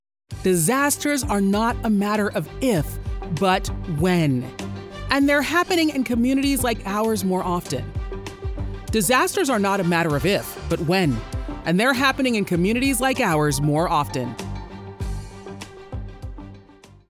Character, Cartoon and Animation Voice Overs
Adult (30-50) | Yng Adult (18-29)